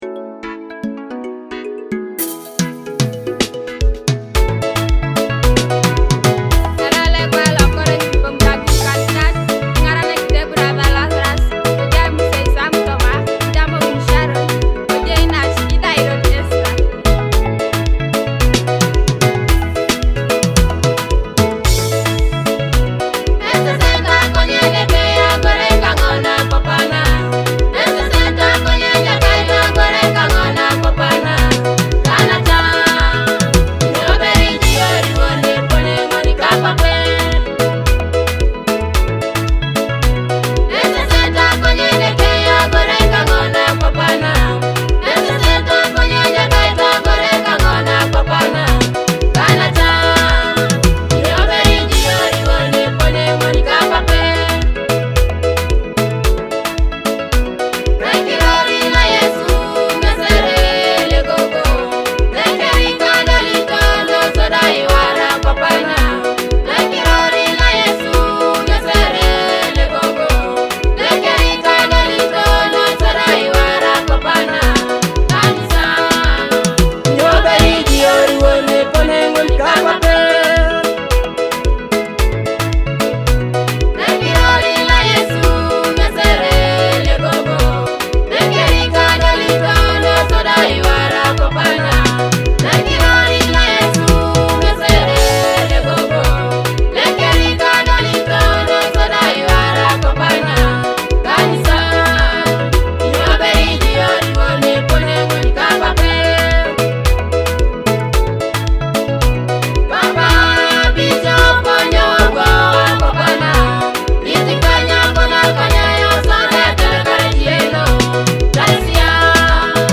powerful and uplifting gospel song